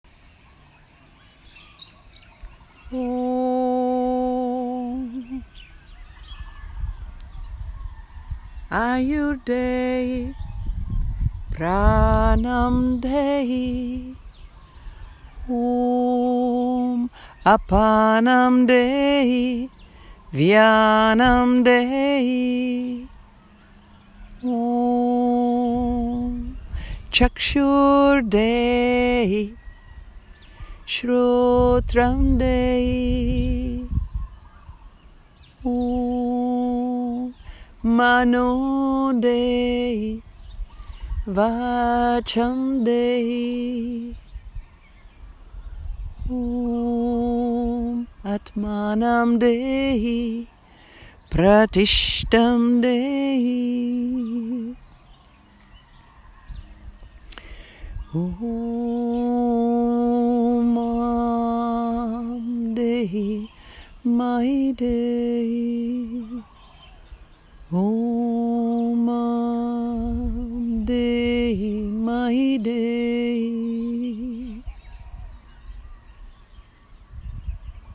Ayurday-Healing-Chant.m4a